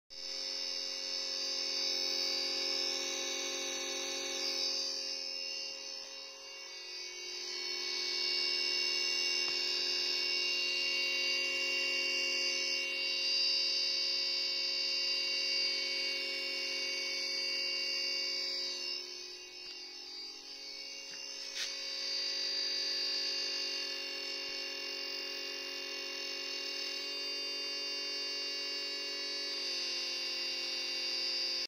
Compresseur climatiseur bruit aigu
Je constate que le moteur tourne en faisant un bruit aigu et strident dès le démarrage et le tube HP ne chauffe pas
Bruit compresseur climatiseur
bruit-compresseur-climatiseur.mp3